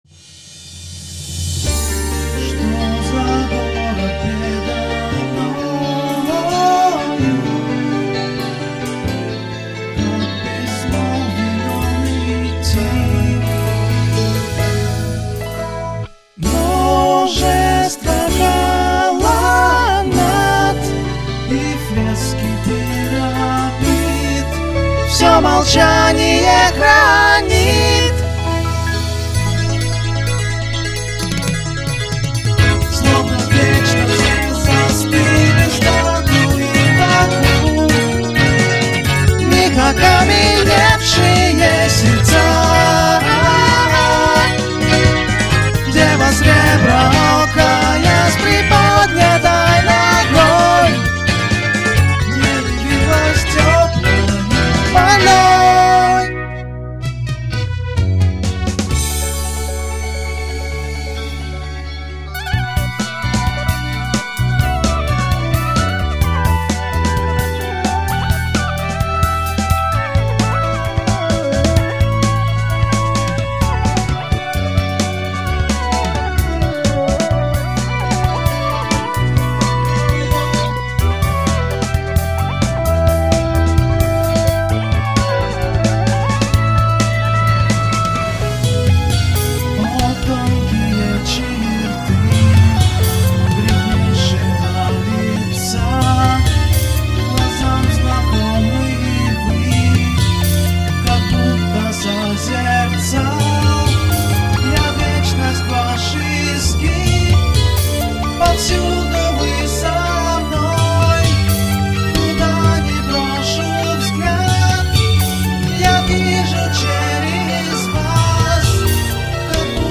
на самом деле, это песня. сырой вариант доступен здесь:
Певец лажает, пищит что-то невнятное. Аккомпонимент - у меня на мобильнике лучше.
Достаточно вялая динамика самой мелодии никак не поддерживает развитие текста. Смысловые акценты не выделяются мелодией и существуют отдельно от нее.